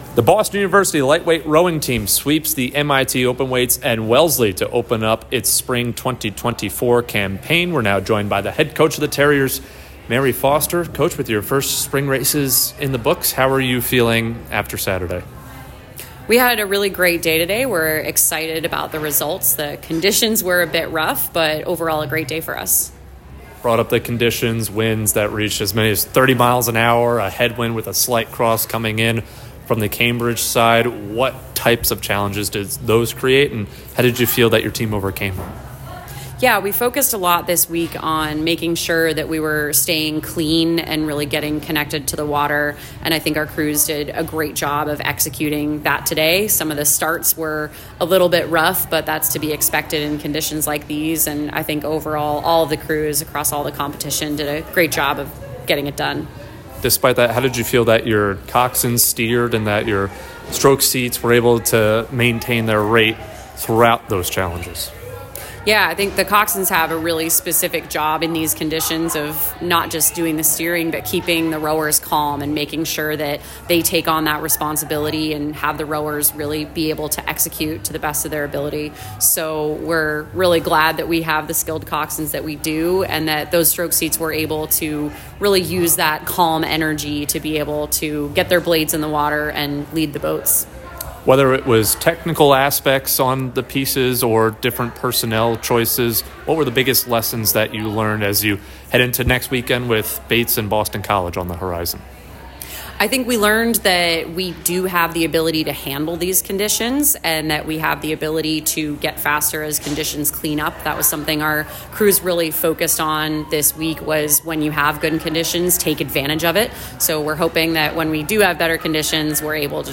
Lightweight Rowing / Wellesley & MIT Postrace Interview
LTROW_Wellesley_MIT_Postrace.mp3